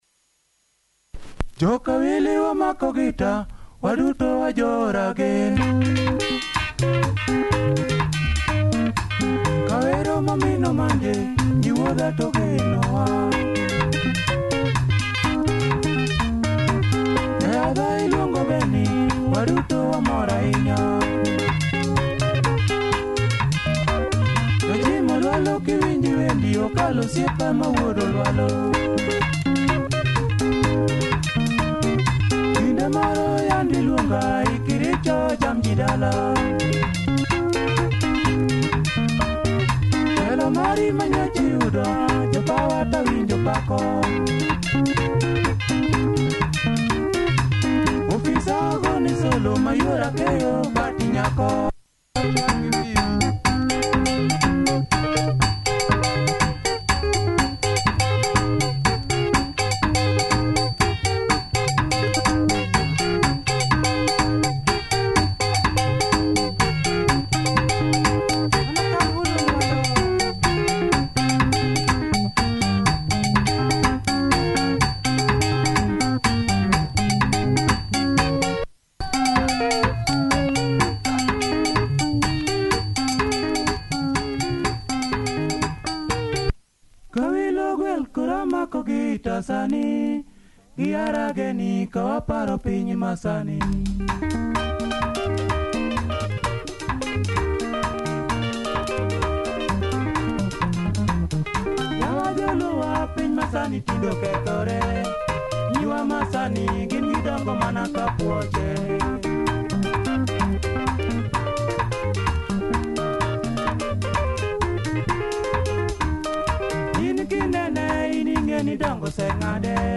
Sassy LUO benga, nice percussive twist, clean copy! https